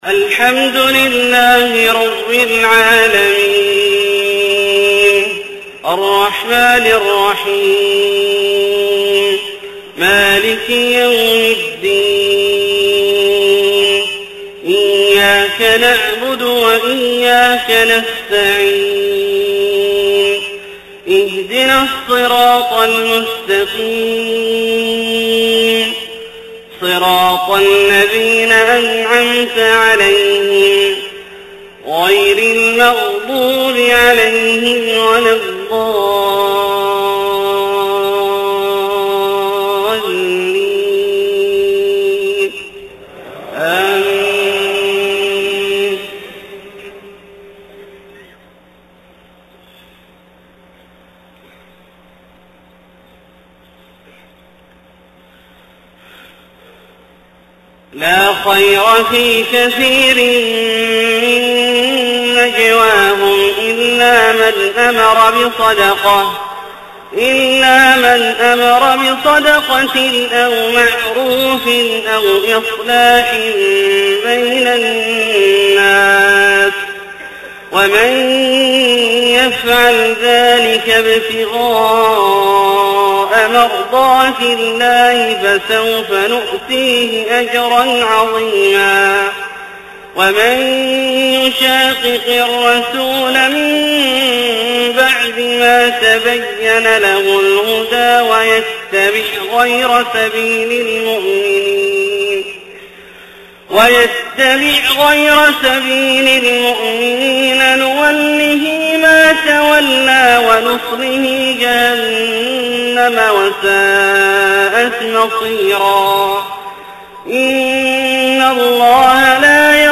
صلاة الفجر 5-1-1431 من سورة النساء {114-126} > ١٤٣١ هـ > الفروض - تلاوات عبدالله الجهني